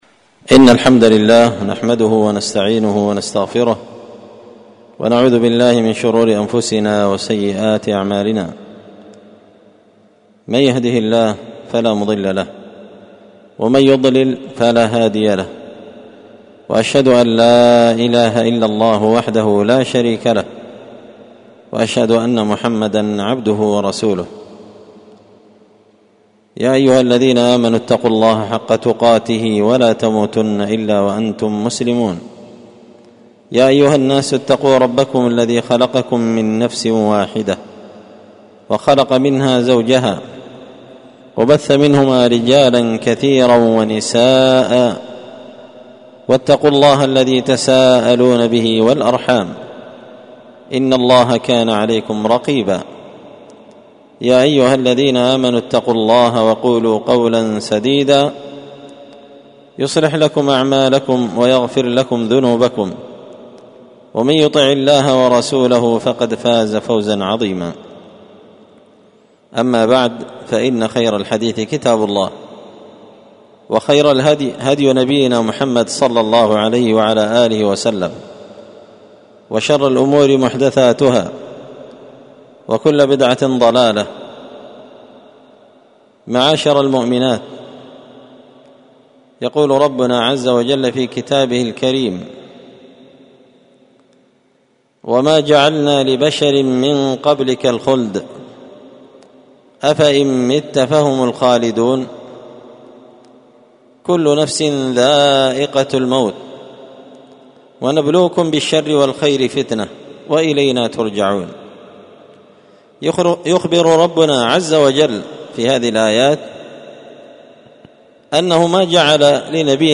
محاضرة للنساء بعنوان:
ألقيت هذه المحاضرة بدار الحـديـث السلفية بمـسجـد الفـرقـان قشن-المهرة-اليمن